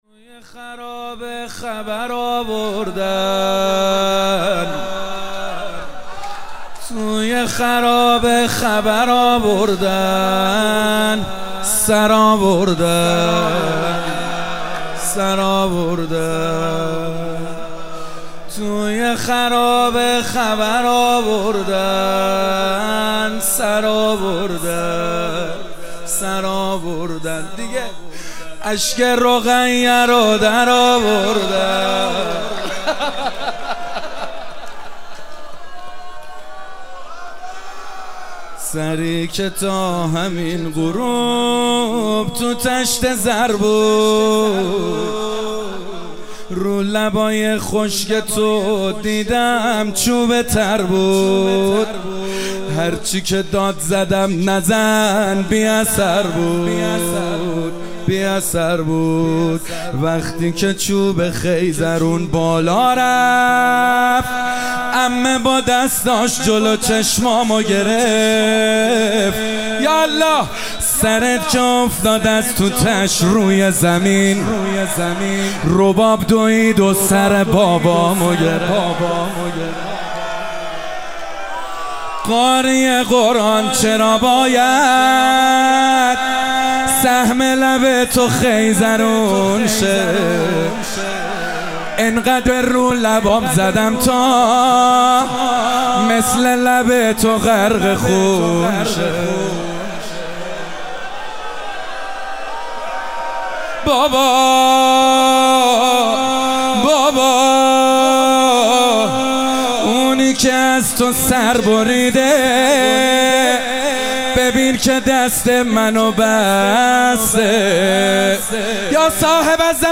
شب سوم ماه رمضان98 - روضه - توی خرابه خبر آوردن